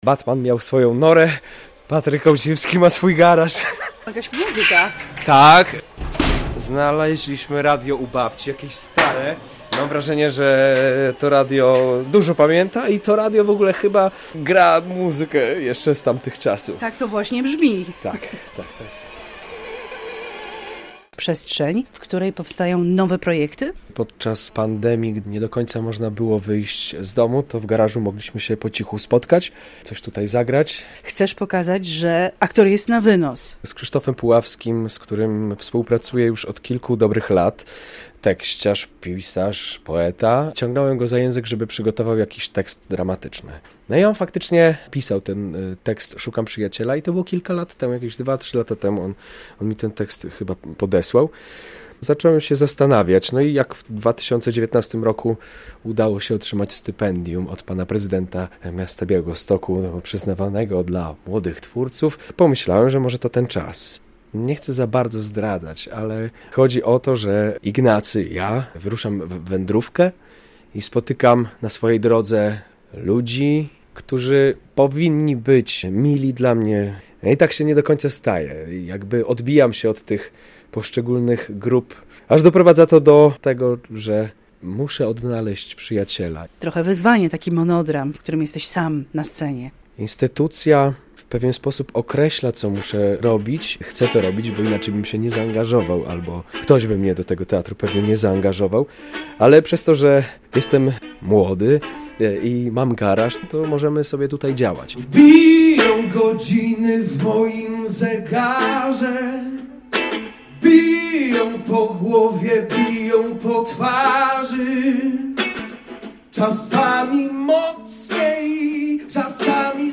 Prowadzący: